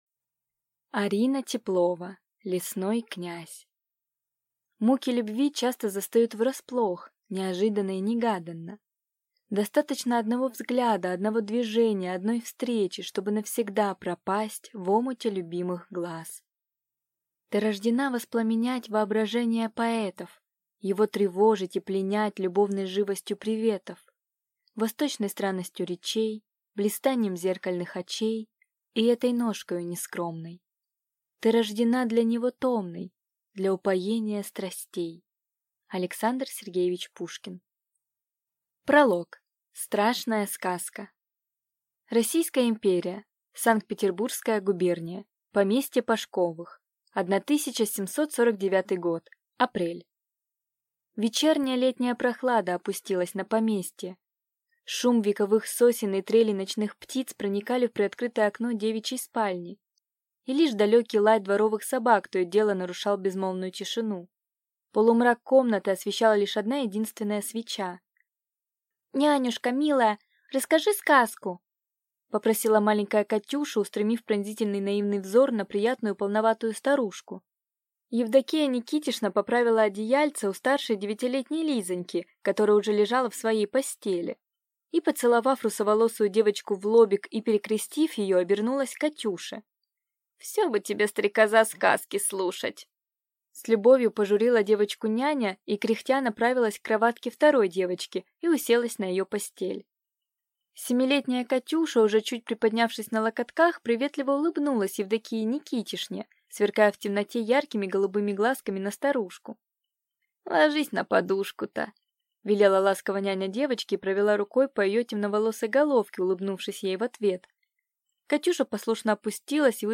Аудиокнига Лесной князь | Библиотека аудиокниг